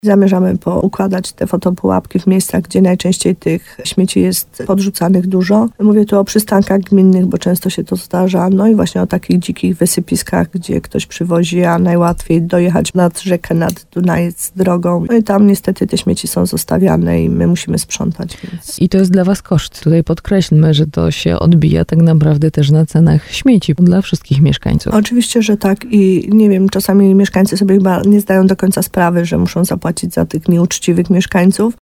– mówi wójt gminy Podegrodzie, Małgorzata Gromala.